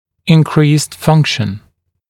[ɪn’kriːst ‘fʌŋkʃn][ин’кри:ст ‘фанкшн]повышенная функция